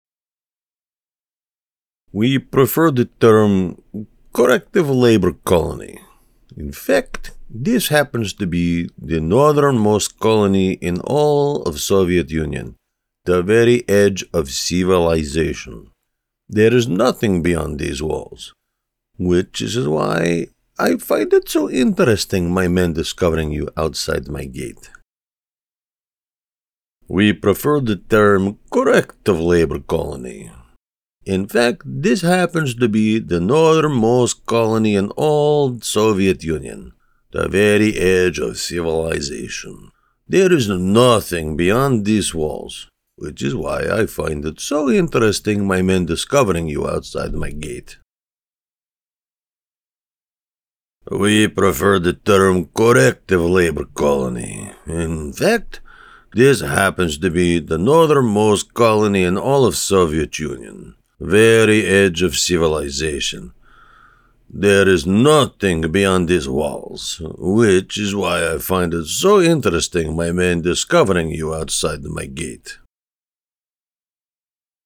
Russian Prison Warden
Senior